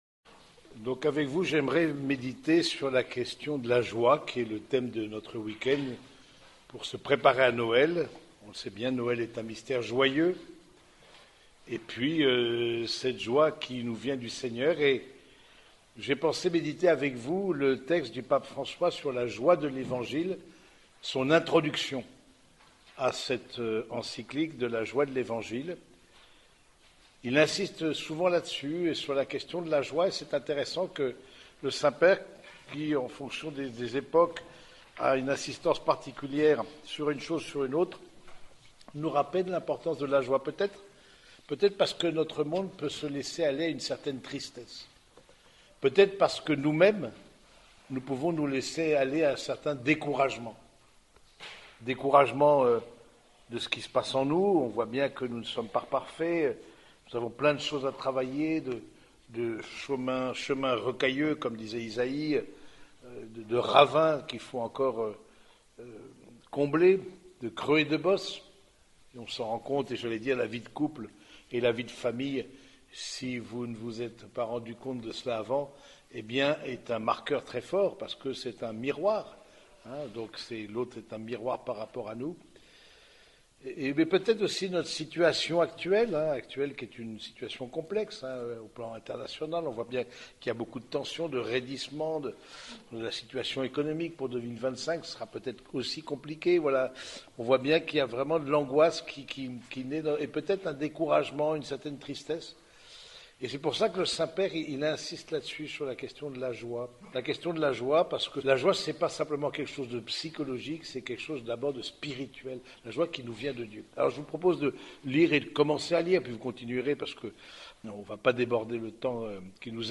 - Week-end Familles